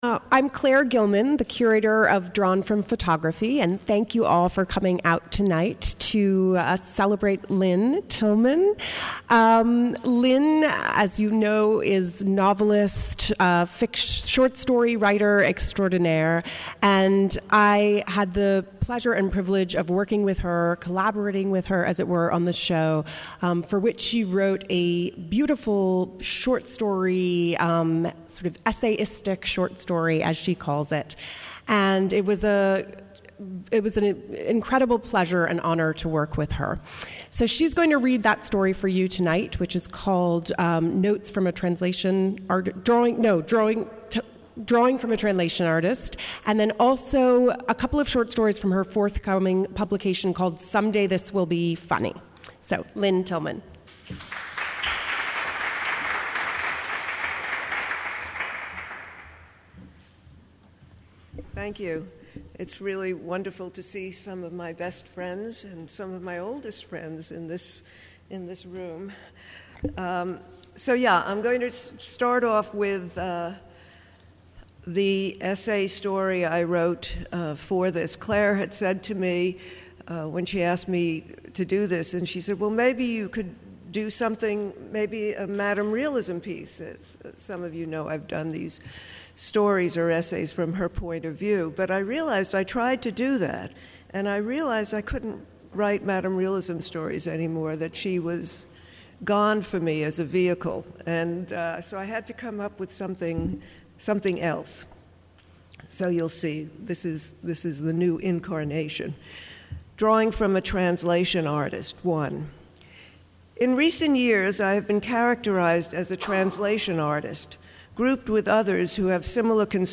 Audio Recording: Reading by Novelist Lynne Tillman | The Bottom Line "The Drawing Center"
On Thursday, March 3, novelist Lynne Tillman performed a reading of her new essayistic story, Drawing from a Translation Artist, which appears in the Drawing Papers edition published in conjunction with Drawn from Photography. She also read from her forthcoming collection of stories, Someday This Will Be Funny.